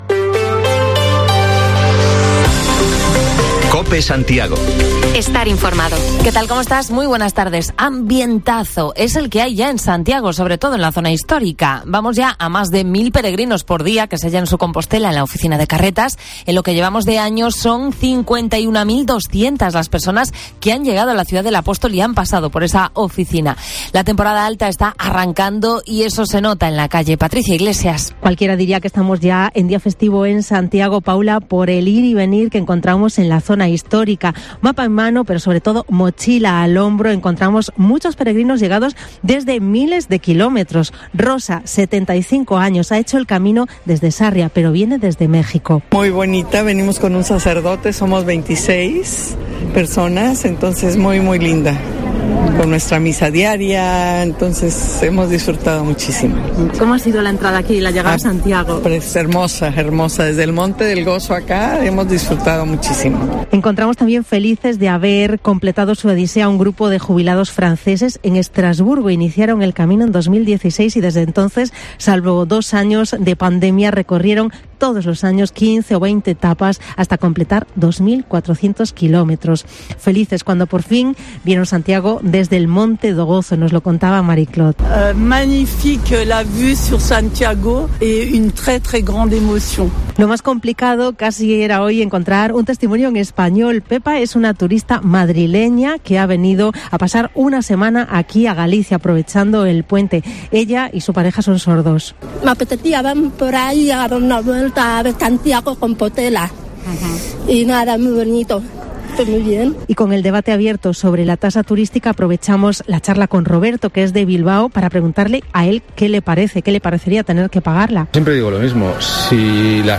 Titulares del día: Ambientazo en la zona histórica ya con el arranque de la temporada alta y con testimonios de turistas y visitantes de Compostela.